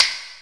SINGLE HITS 0009.wav